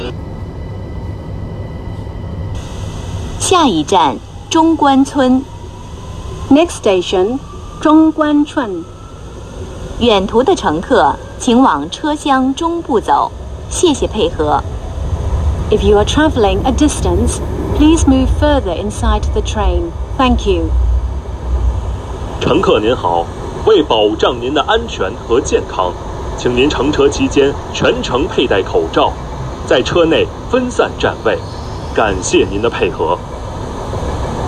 2020년 3월 23일, 모든 승객이 마스크를 착용해야 한다는 4호선 열차 안내 방송 녹음